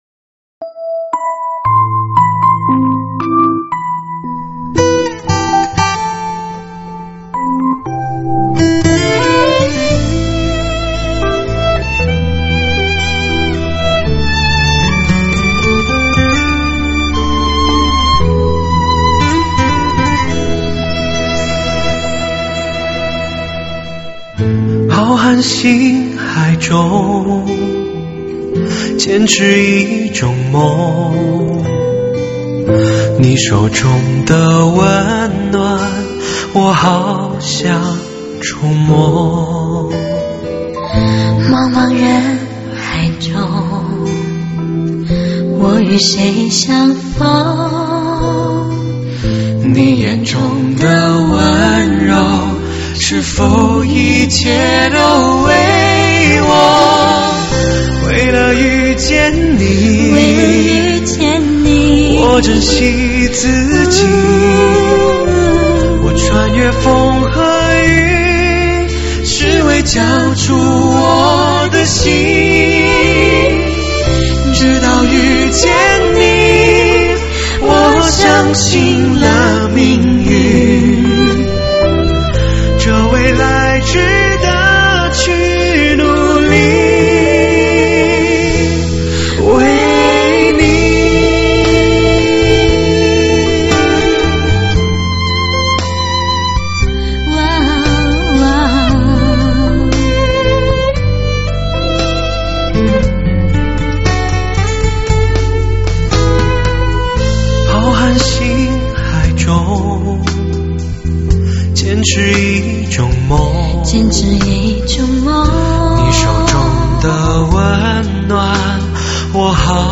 品味好音乐，似水如歌，温婉可人，时间总在不经意间流逝，尘封的记忆也在这怀念的旋律中逐渐打开……